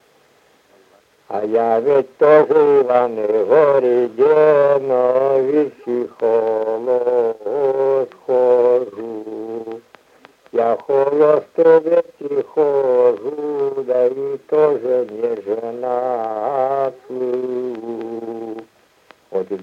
Произношение фрикативного /ɣ/ вместо смычного /г/